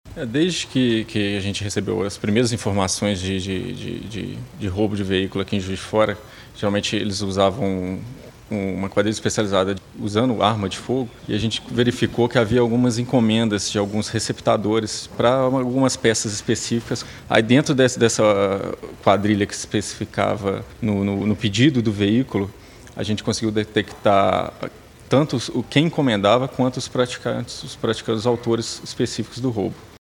O Delegado Regional de Juiz de Fora, Armando Avólio, responsável pelas investigações, conversou com a equipe da Itatiaia.
Delegado Regional de Juiz de Fora, Armando Avólio